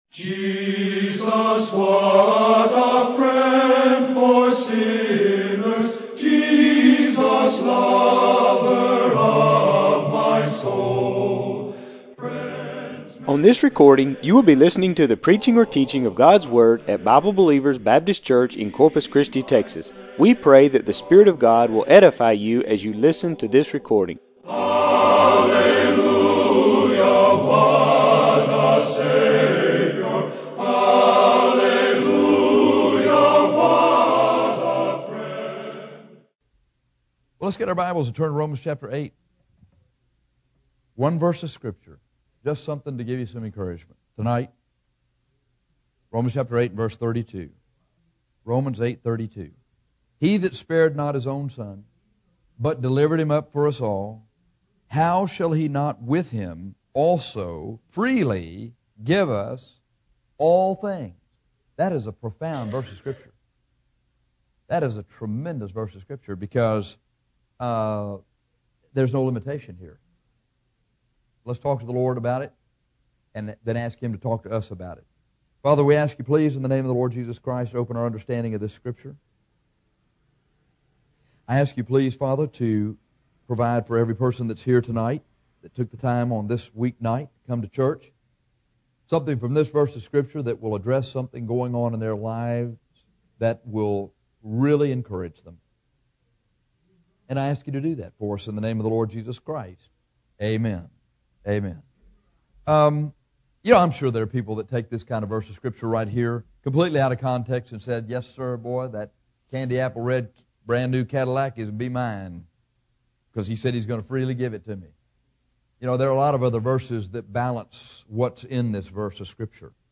This is a Wednesday night sermon about the promise that the Lord will freely give us all things seeing that salvation itself is free gift.